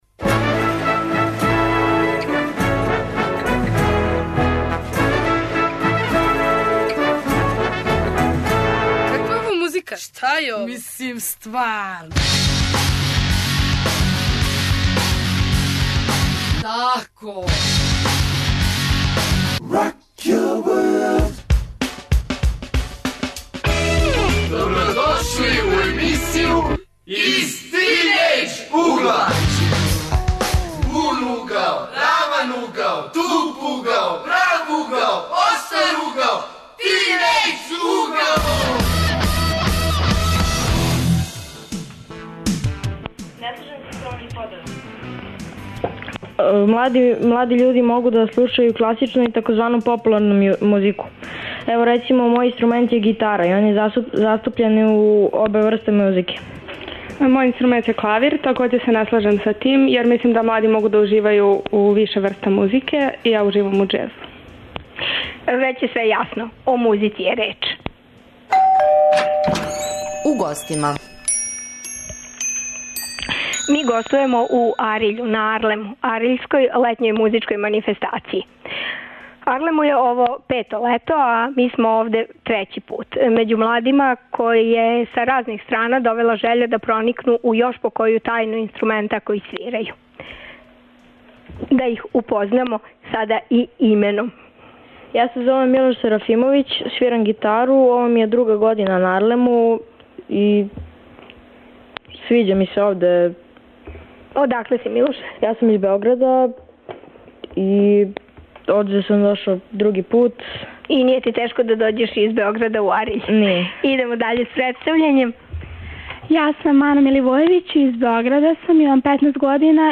Ове суботе дружимо се с виолончелистима, пијанистима, гитаристима и виолинистима који са разних страна долазе да на обалама трију ариљских река и међу малињацима комбинују летовање и вежбање.